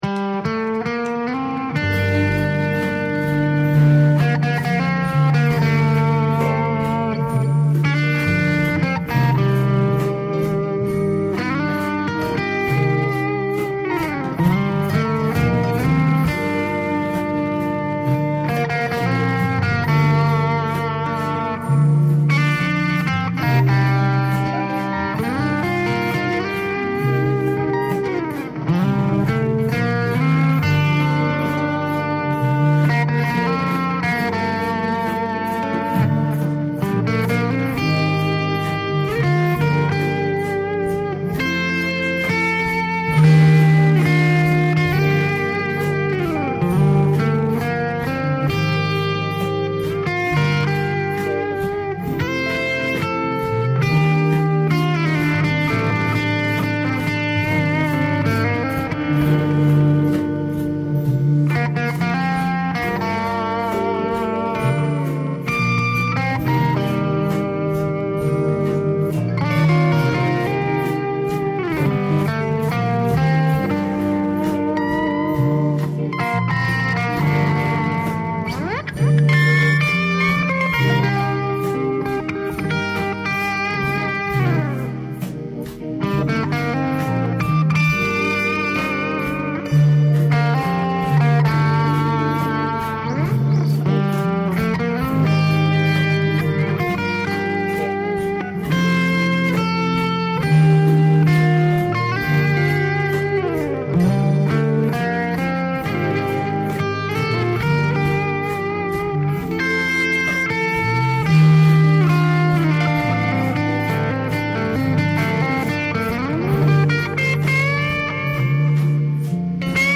Guitar Works